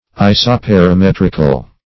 Search Result for " isoperimetrical" : The Collaborative International Dictionary of English v.0.48: Isoperimetrical \I`so*per`i*met"ric*al\, a. [Gr.